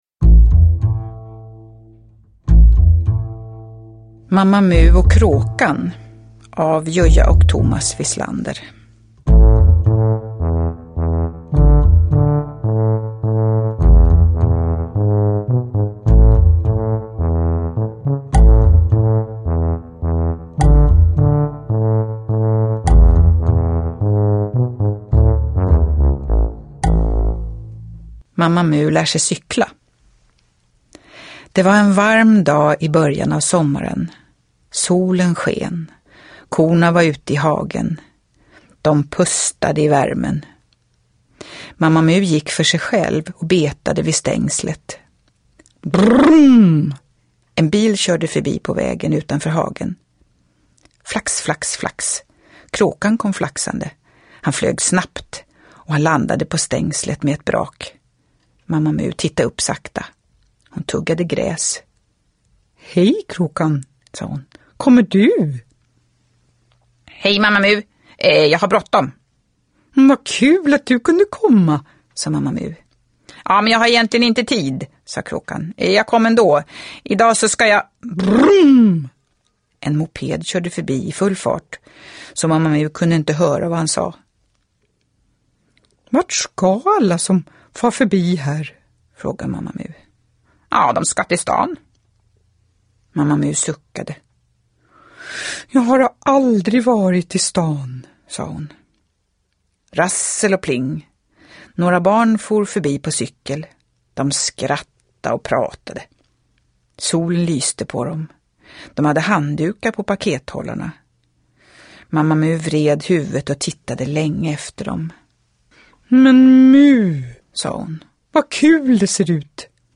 Downloadable Audiobook